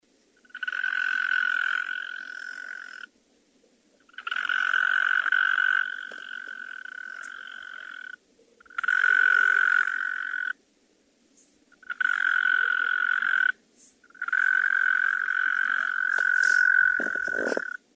На этой странице собраны звуки медведки — стрекотание и другие характерные шумы, которые издает это насекомое.
Звук самца медведки в ночи